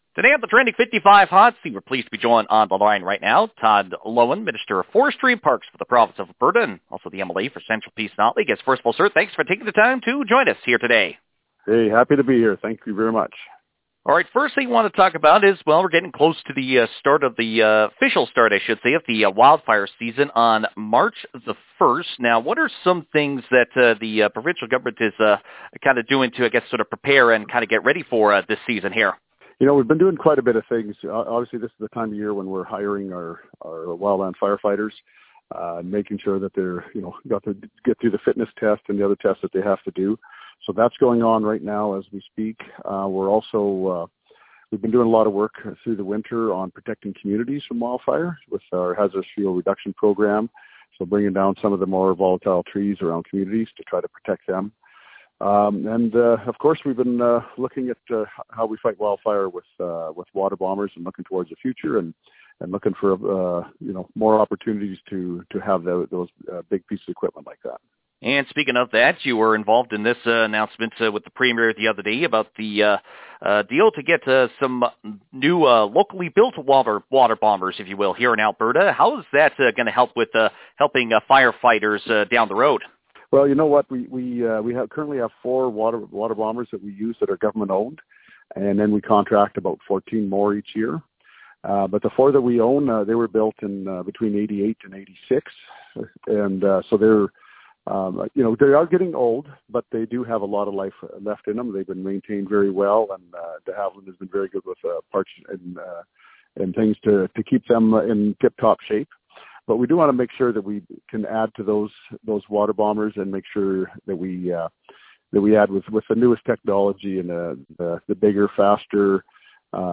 Forestry and Parks Minister Todd Loewen says there are a number of things happening on that front.